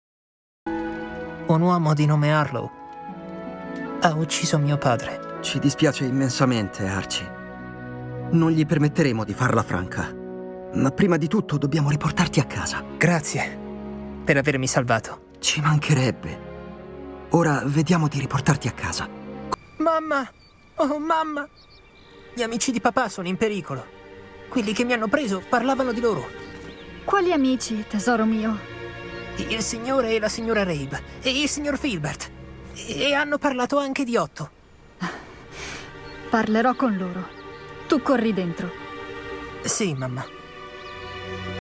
TELEFILM